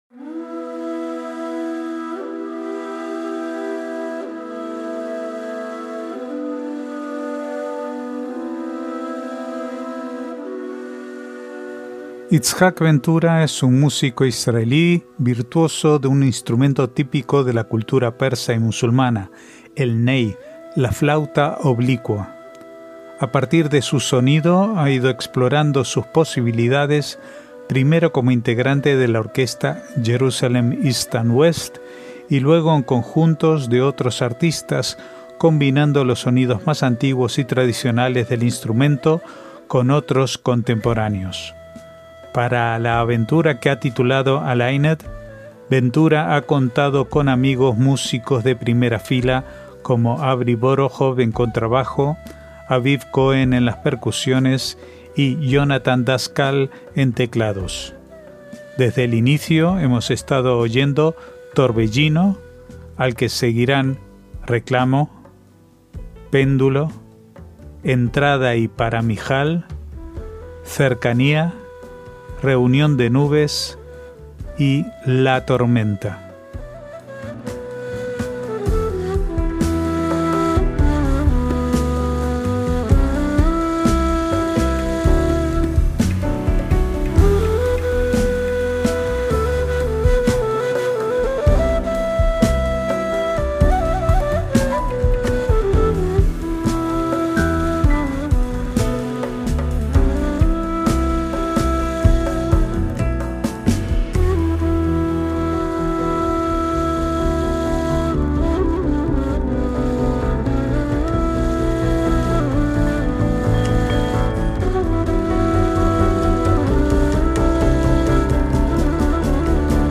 MÚSICA ISRAELÍ
el ney, la flauta oblicua.
en contrabajo
en las percusiones
en teclados